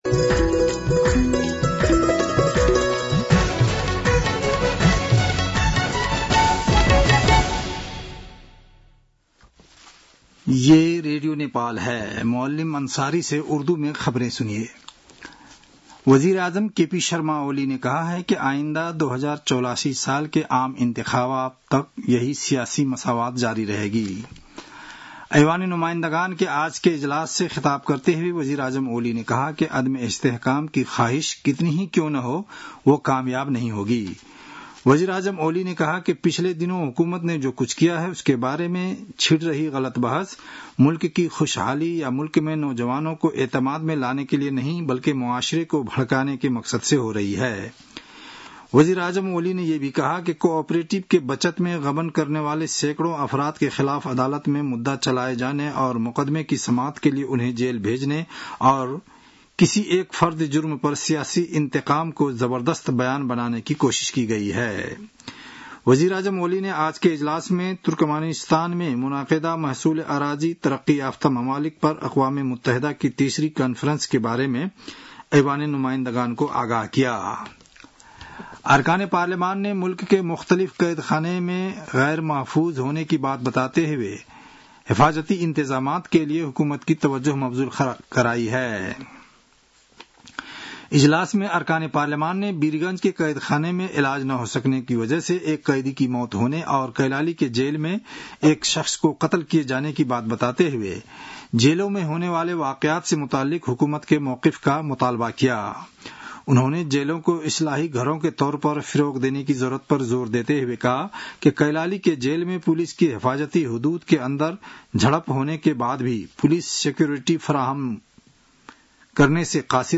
उर्दु भाषामा समाचार : २८ साउन , २०८२